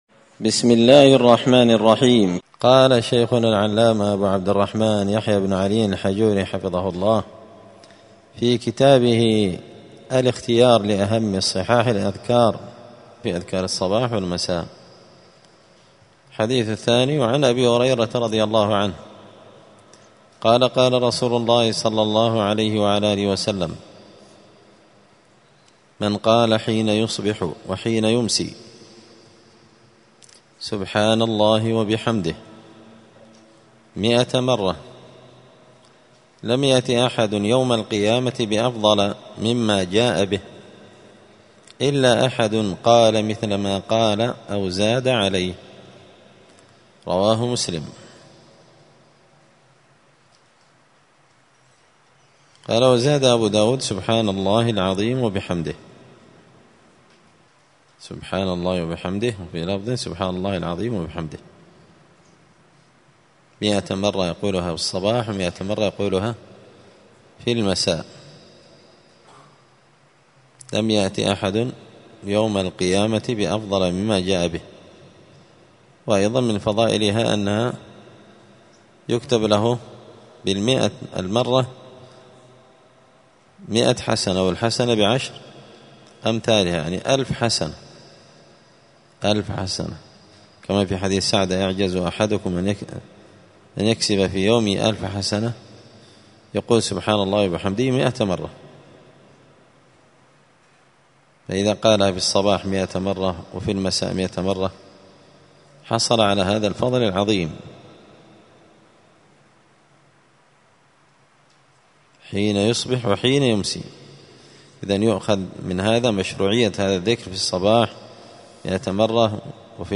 *{الدرس الثامن (8) الحديث الثاني من أذكار الصباح والمساء}*